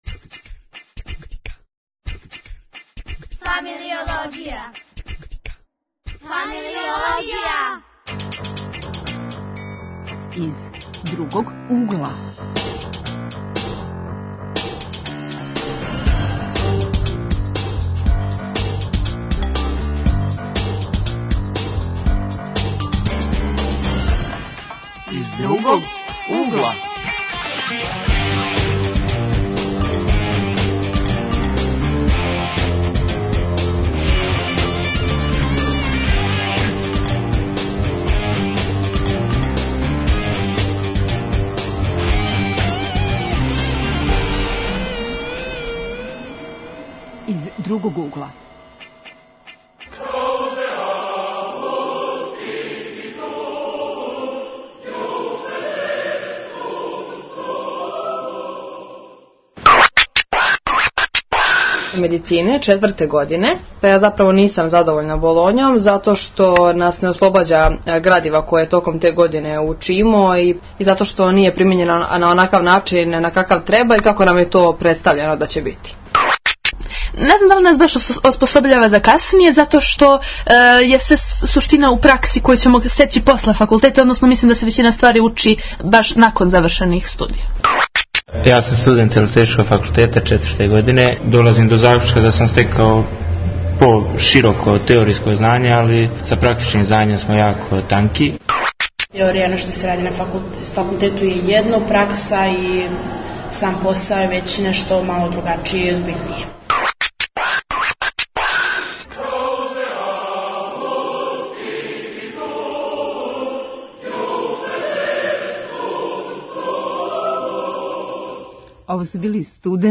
Гости су нам студенти.